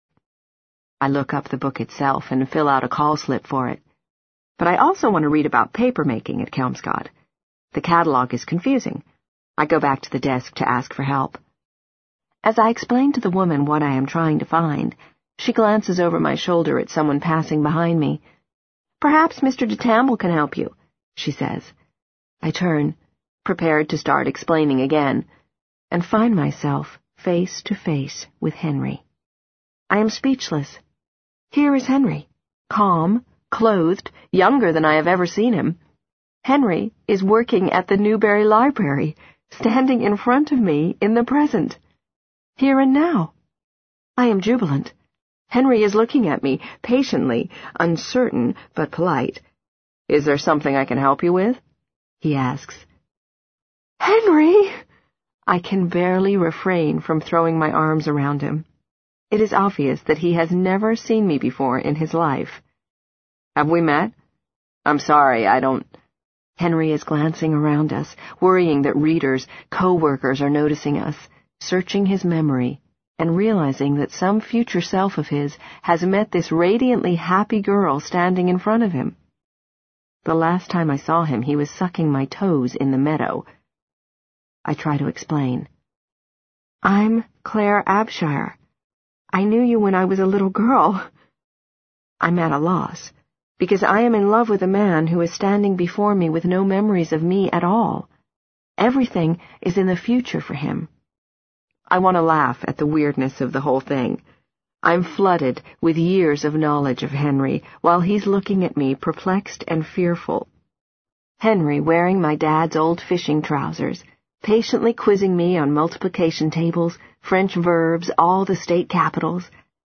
在线英语听力室【时间旅行者的妻子】06的听力文件下载,时间旅行者的妻子—双语有声读物—英语听力—听力教程—在线英语听力室